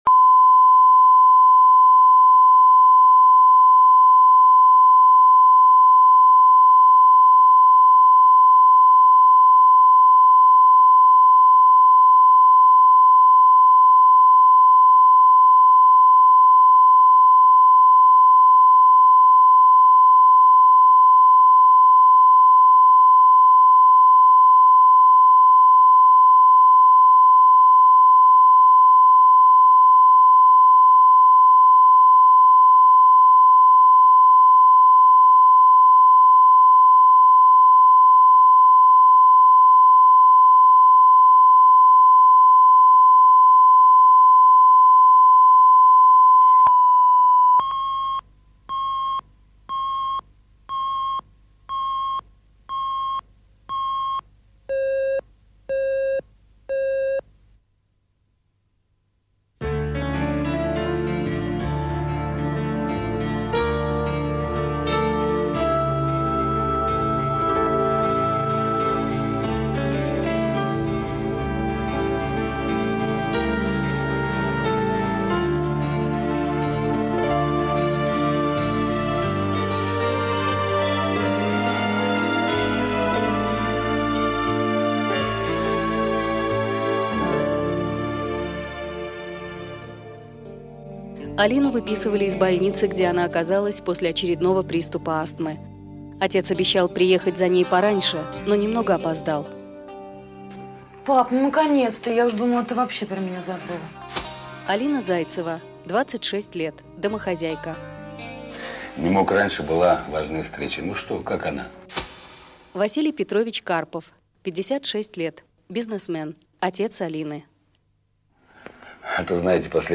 Аудиокнига Разбитое сердце | Библиотека аудиокниг